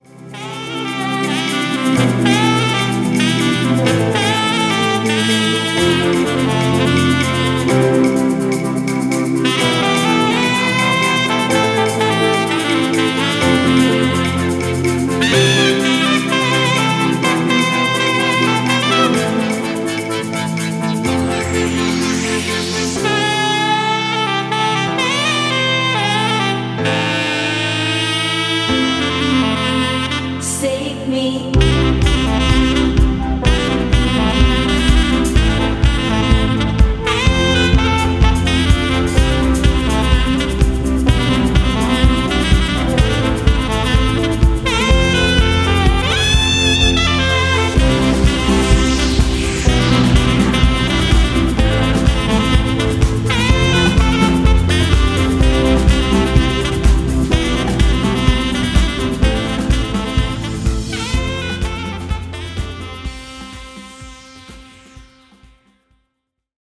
Saxophonistin